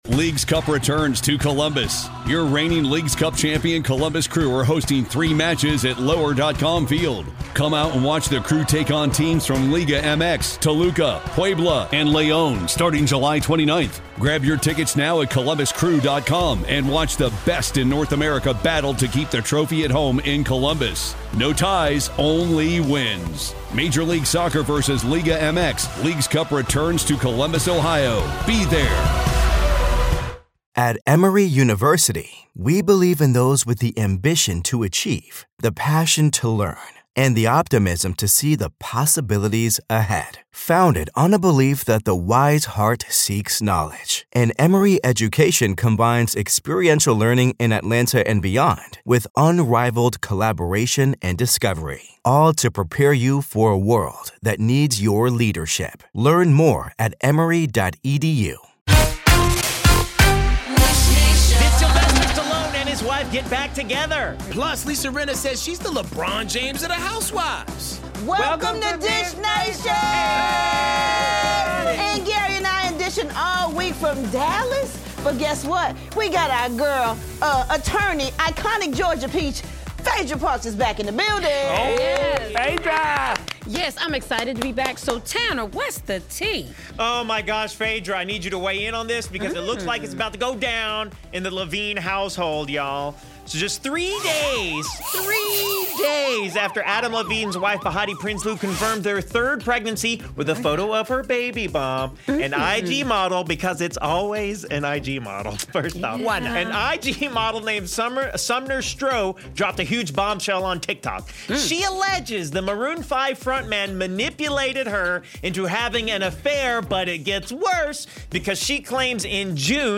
Plus, 'RHOA' veteran Phaedra Parks is back in the studio to co-host with us, so tune in to today's Dish Nation for more!